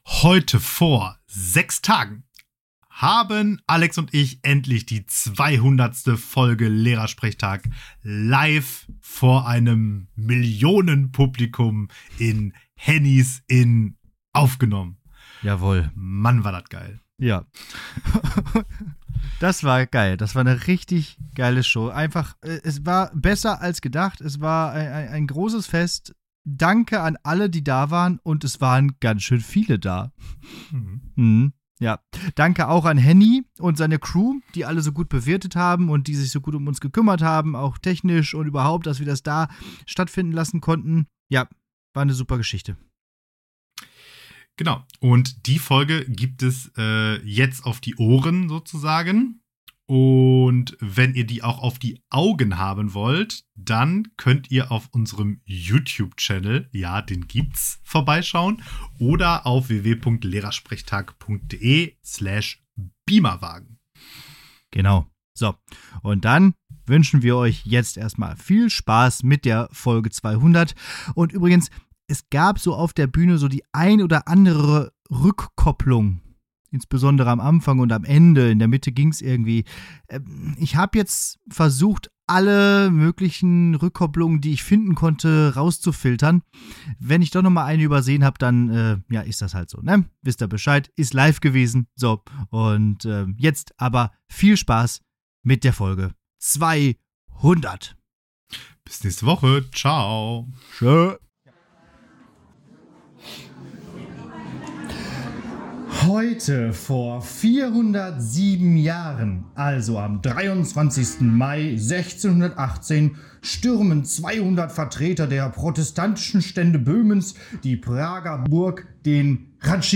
#200 Live auf der Bühne ~ Lehrersprechtag Podcast
Beschreibung vor 10 Monaten Die beiden Studienräte treten nach 200 Folgen hinaus aus dem muffigen Mikrokosmos des Podcaststudios und besteigen die Bretter, die die Welt bedeuten.